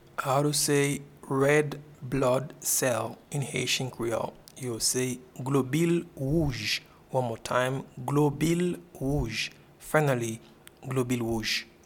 Pronunciation and Transcript:
Red-blood-cell-in-Haitian-Creole-Globil-wouj.mp3